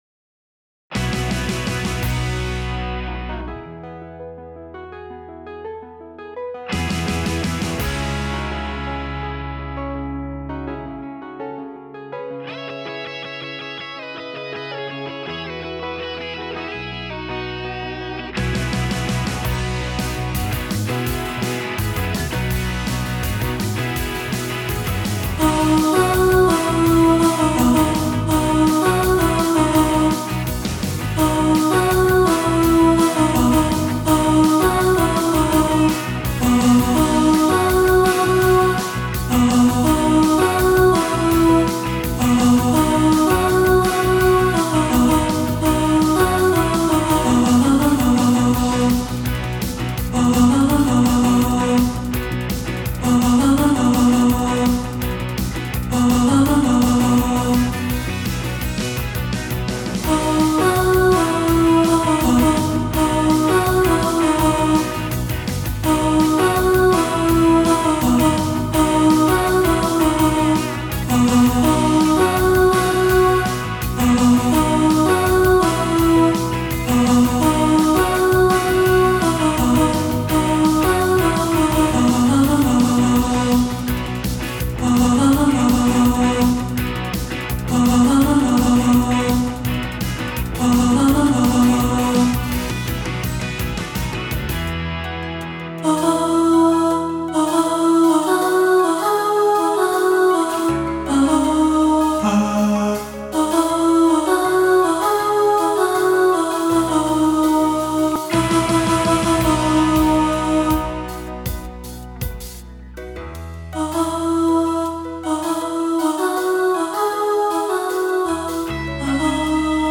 Hole-In-Your-Soul-Alto.mp3